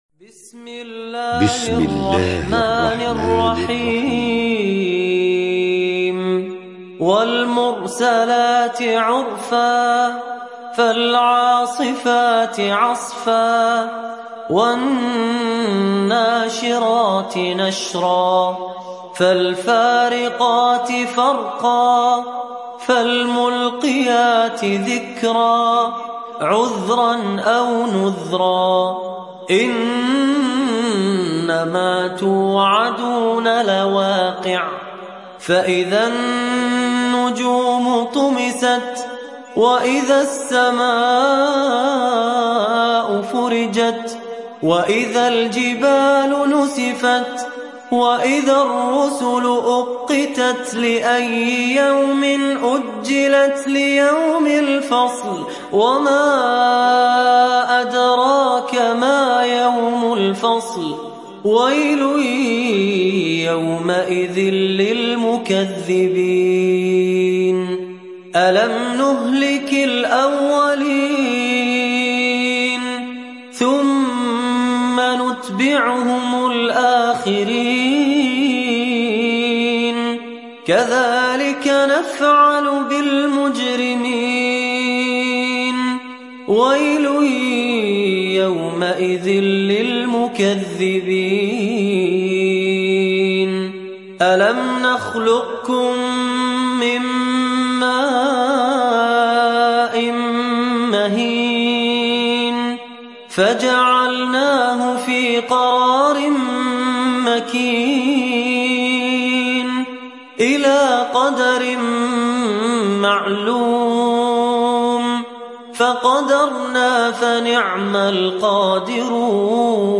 تحميل سورة المرسلات mp3 بصوت فهد الكندري برواية حفص عن عاصم, تحميل استماع القرآن الكريم على الجوال mp3 كاملا بروابط مباشرة وسريعة